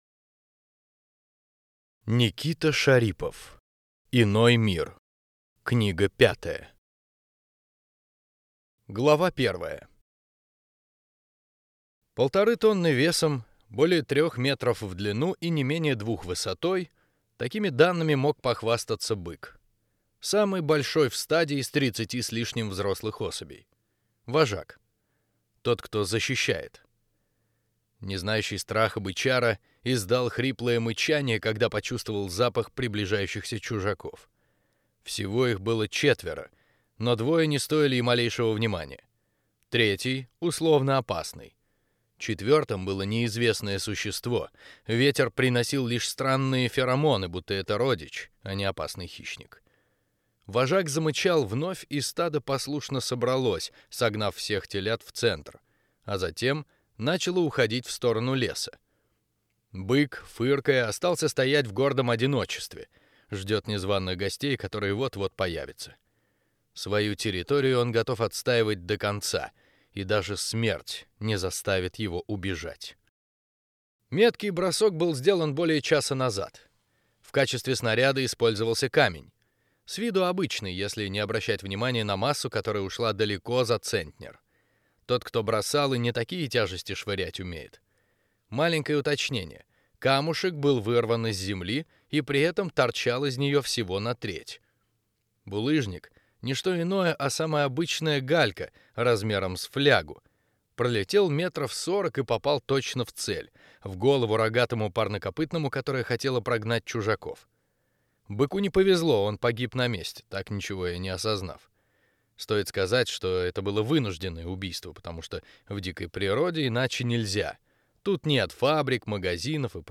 Аудиокнига Иной мир. Дорога домой | Библиотека аудиокниг